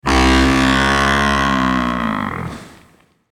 Animal Buffalo Sound